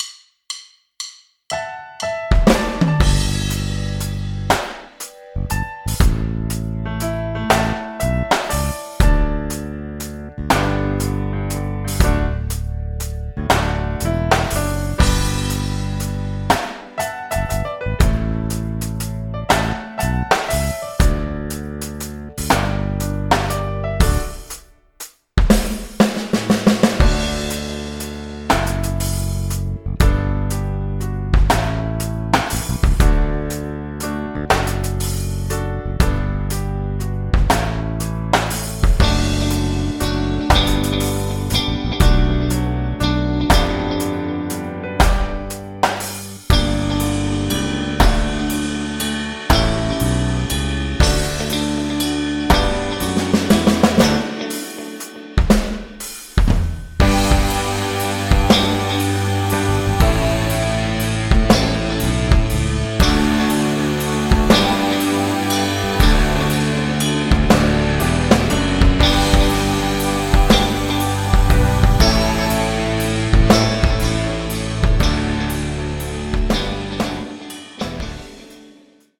playback, karaoke, instrumental